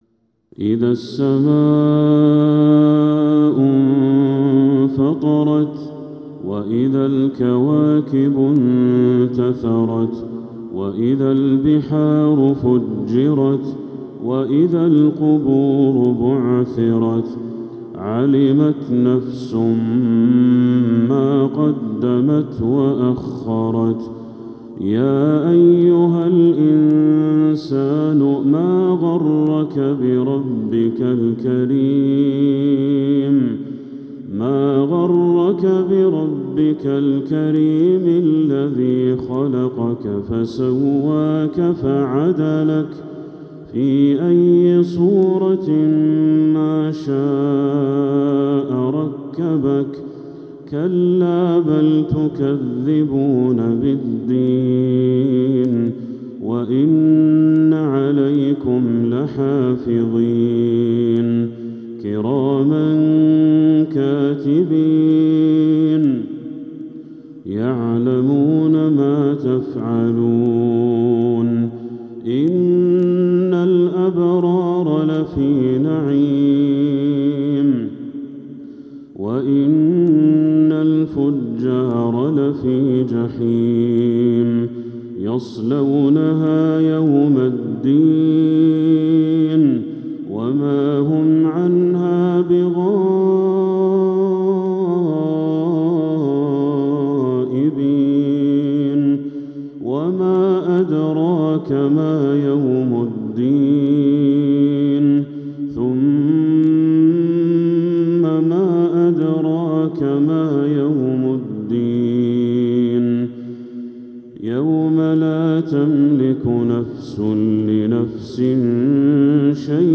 سورة الإنفطار كاملة | محرم 1447هـ > السور المكتملة للشيخ بدر التركي من الحرم المكي 🕋 > السور المكتملة 🕋 > المزيد - تلاوات الحرمين